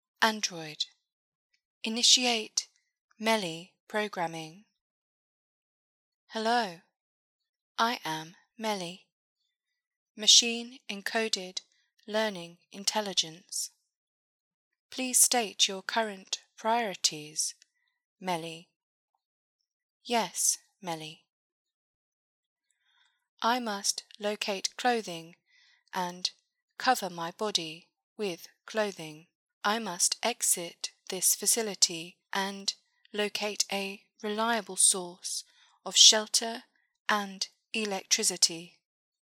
Robot Voice Audio Tutorial
If the idea is to sound like a robot, then the focus should be on sounding artificial, emotionless, and machine-like.
MELI-example-Noise-Reduction.mp3